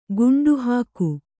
♪ guṇḍu hāku